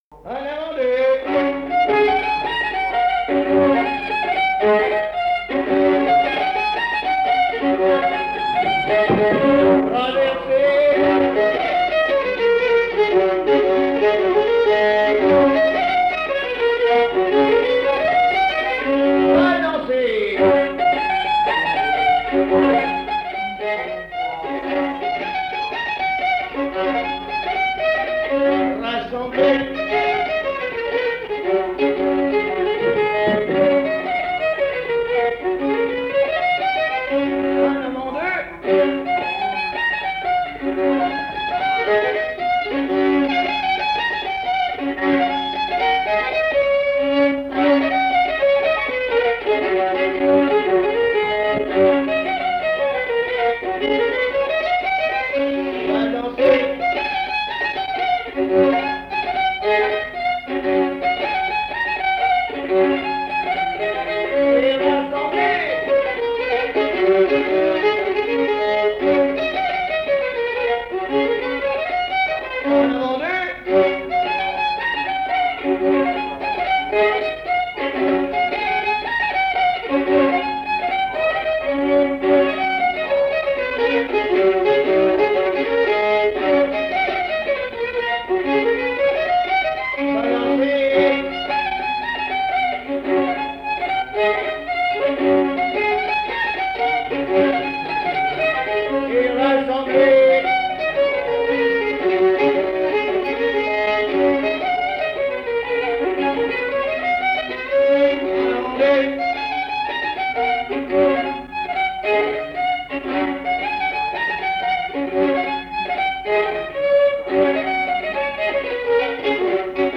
Avant-deux
Résumé instrumental
Répertoire du violoneux
Pièce musicale inédite